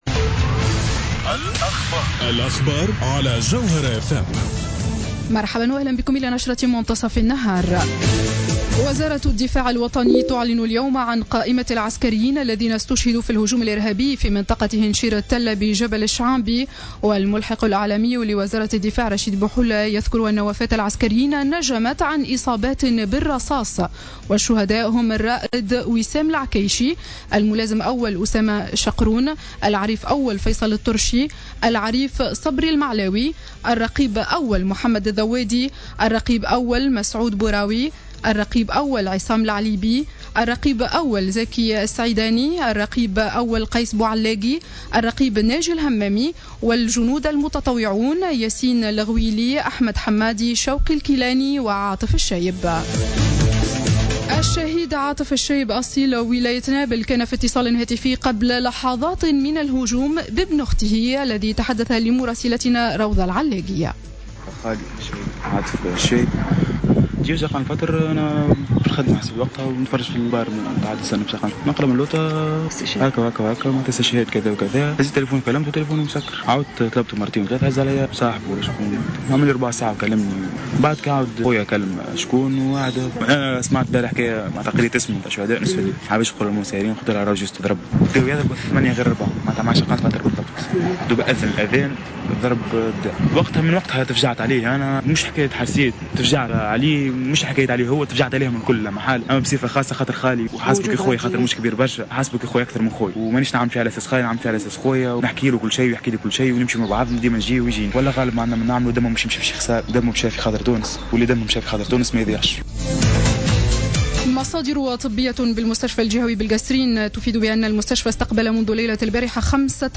نشرة أخبار منتصف النهار ليوم الخميس 17-07-14